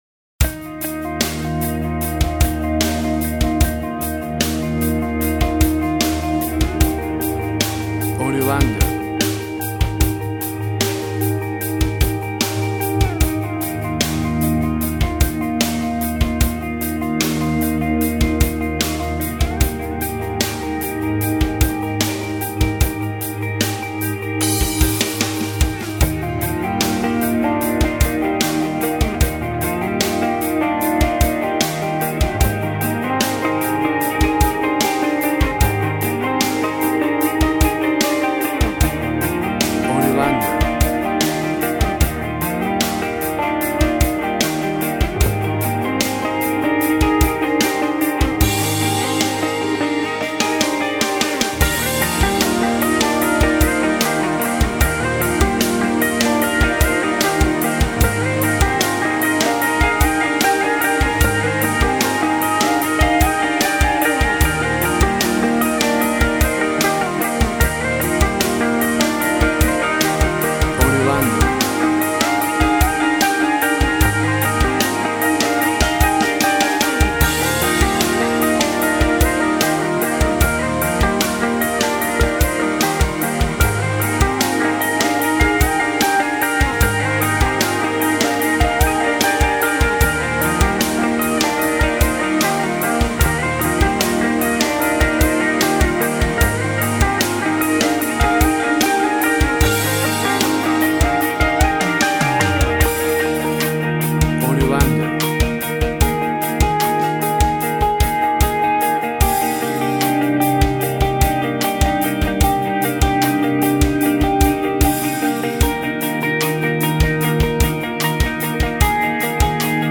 Tempo (BPM) 76